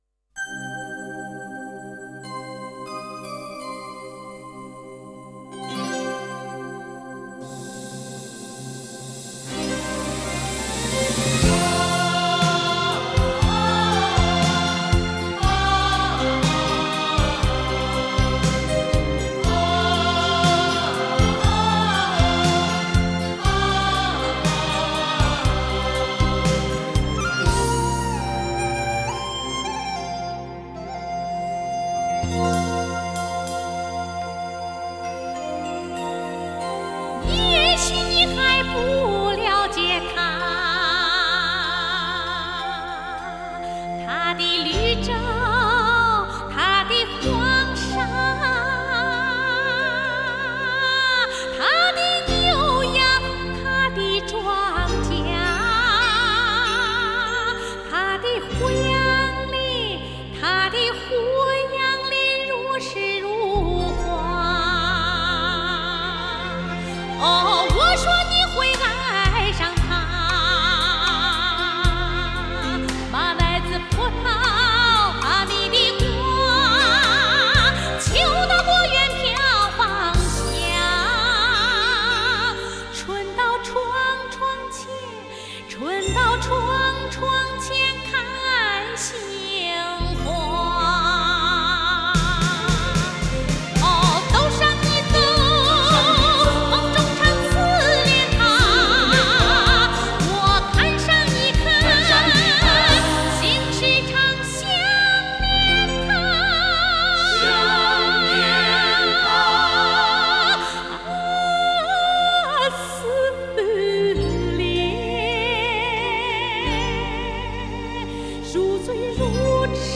Жанр: Chinese pop ∕ Chinese folk